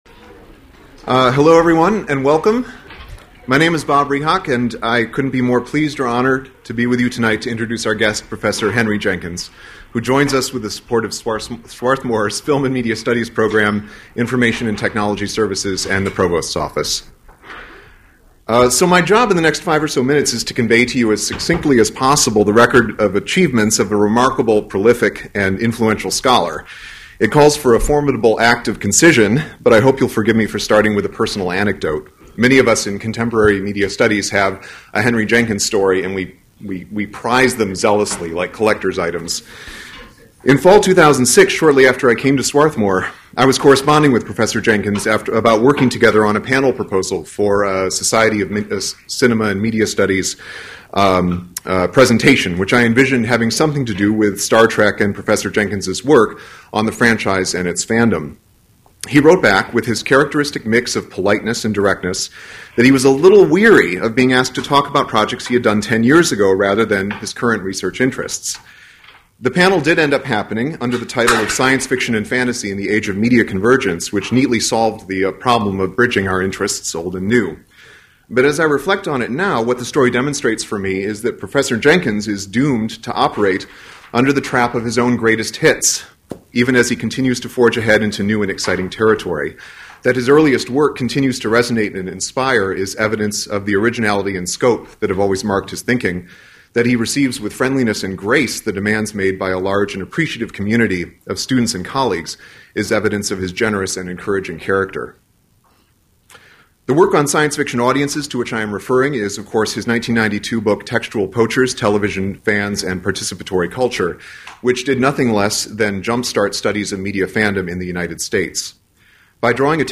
New media and popular culture scholar Henry Jenkins speaks on the shift from sticky to spreadable media, focusing on the ways individuals consume, share, and spread information within a larger social, political, and cultural context.